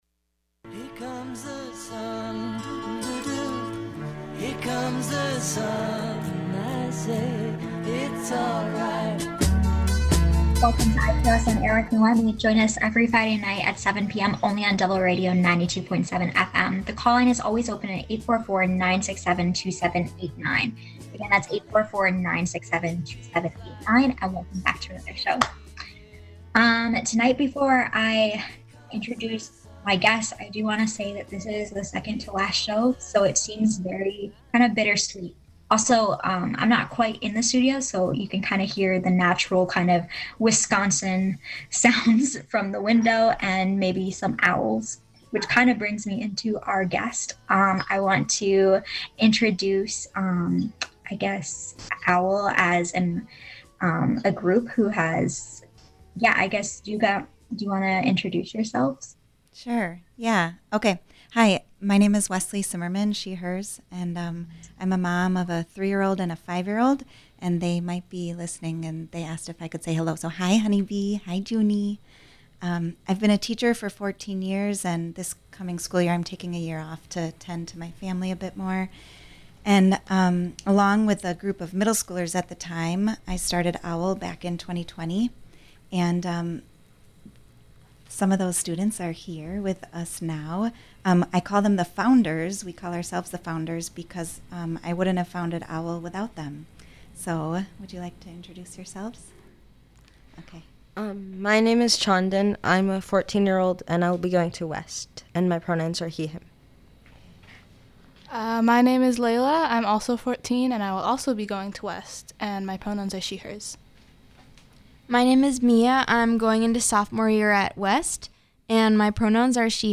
Special musical guest Flying Fuzz.